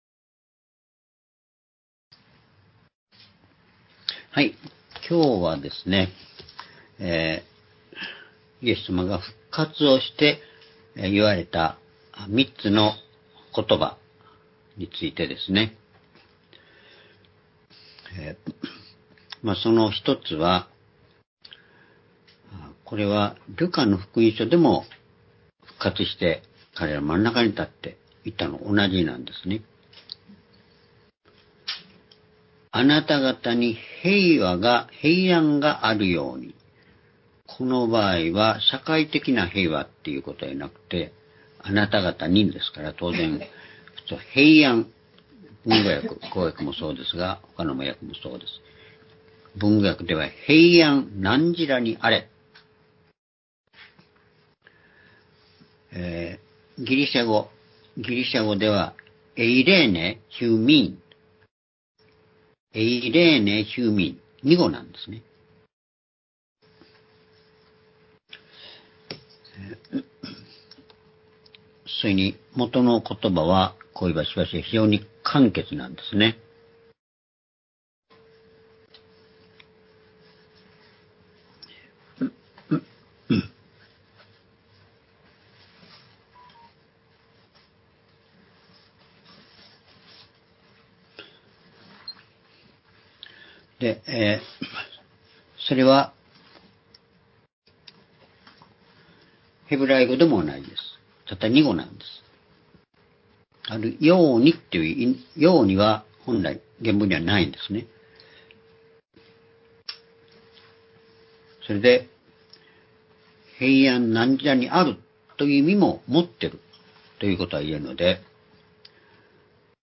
主日礼拝日時 ２０２５年４月２７日（主日礼拝） 聖書講話箇所 「復活したイエスの三つのことば」 ヨハネ２０章２１節～２３節 ※視聴できない場合は をクリックしてください。